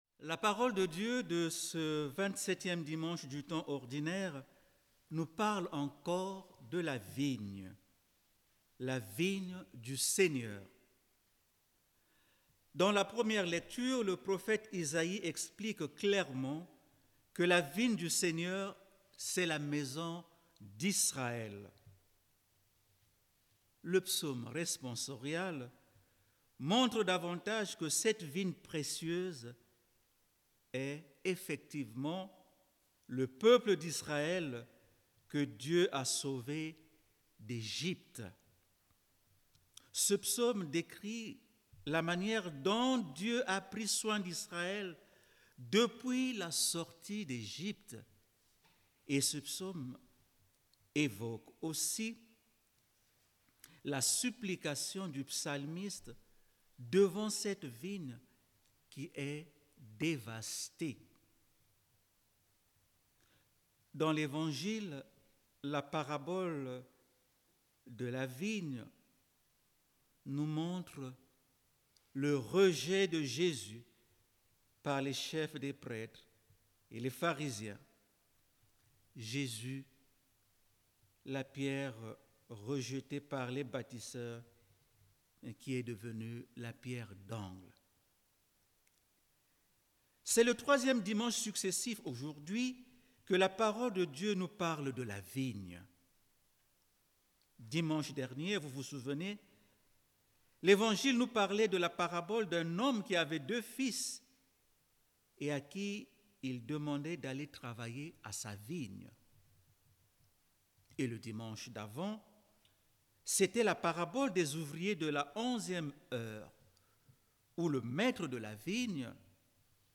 Grâce à un enregistrement en direct , ceux qui le souhaitent peuvent écouter sa réflexion. Que nous dit cette ancienne parabole aujourd'hui, surtout en temps de crise où tout le monde semble angoissé et incertain ?